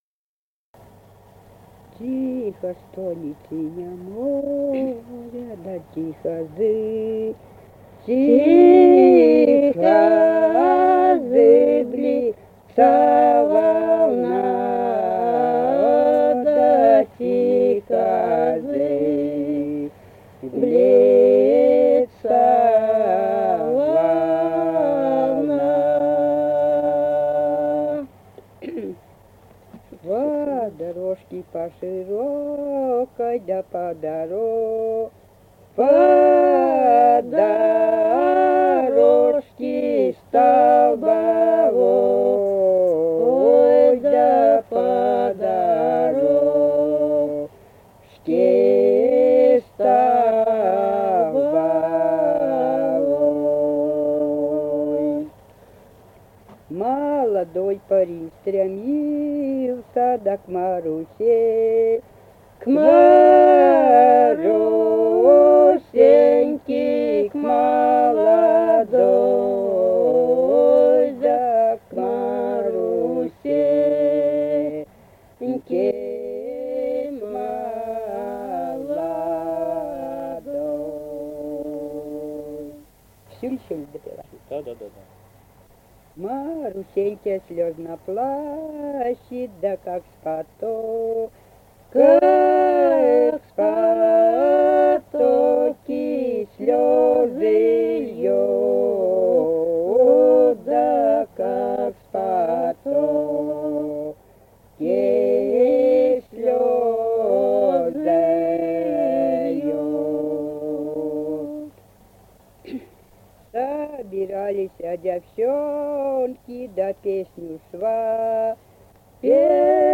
Русские песни Алтайского Беловодья 2 «Тихо стонет сине море», лирическая.
Республика Казахстан, Восточно-Казахстанская обл., Катон-Карагайский р-н, с. Белое / с. Печи.